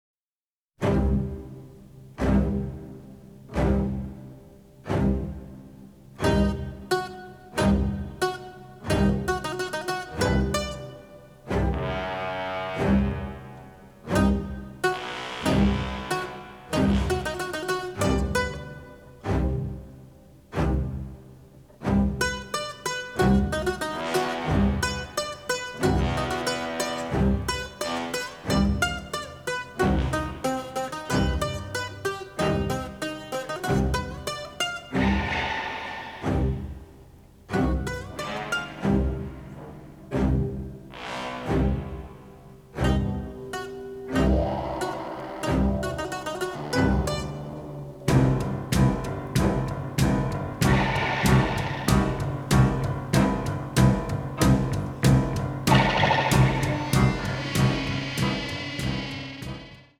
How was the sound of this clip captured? The Original Album (stereo)